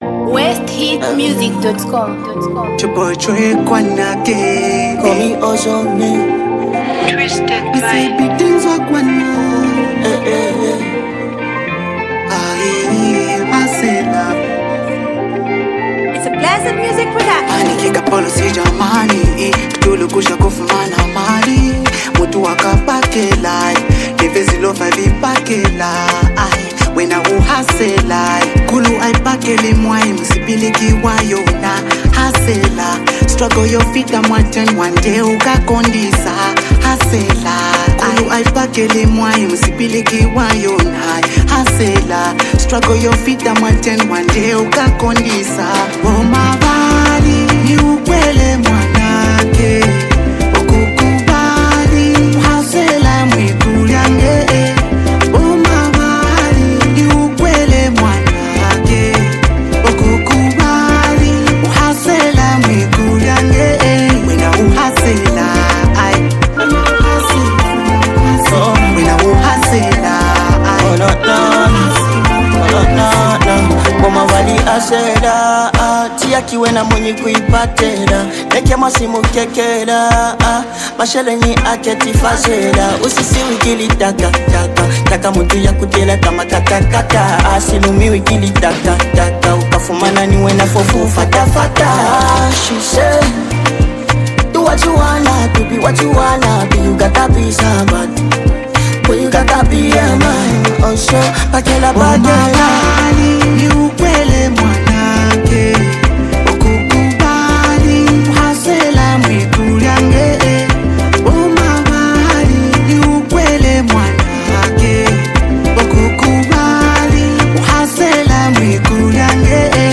inspiring new track
powerful melodies
energetic verses
a solid and motivational sound